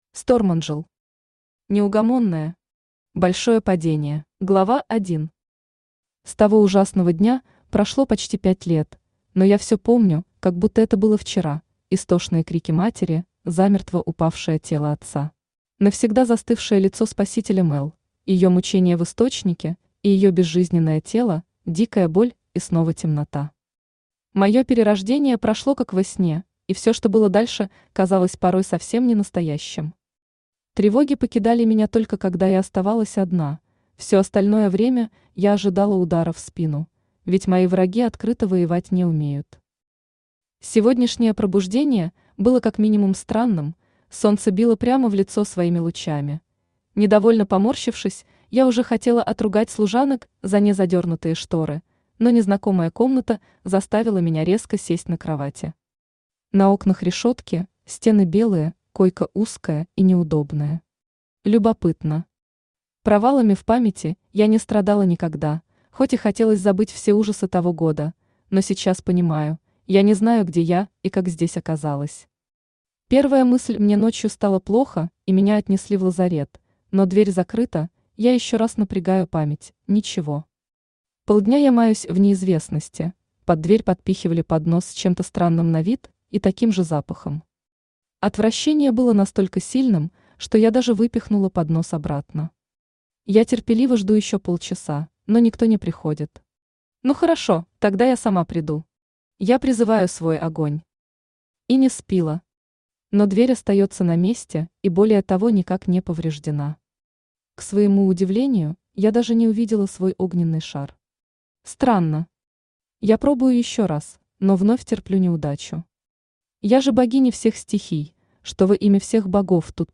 Аудиокнига Неугомонная. Большое падение | Библиотека аудиокниг
Большое падение Автор Stormangel Читает аудиокнигу Авточтец ЛитРес.